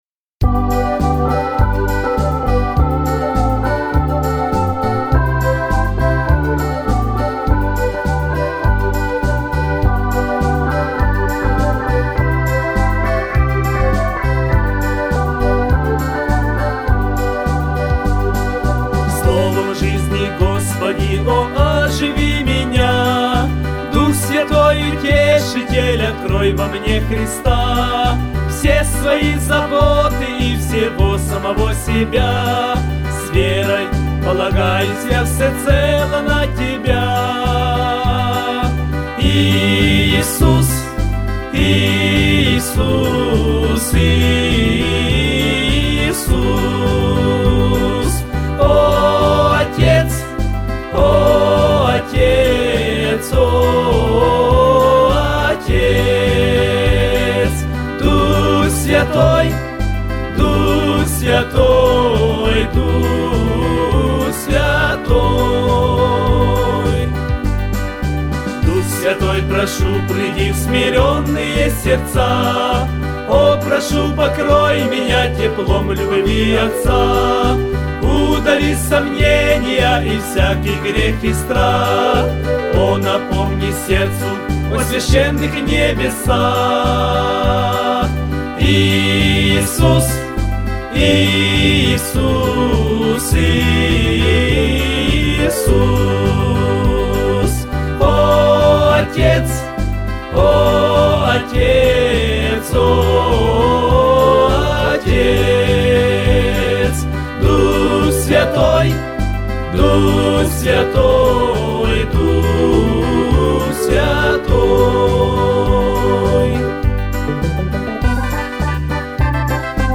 274 просмотра 811 прослушиваний 41 скачиваний BPM: 77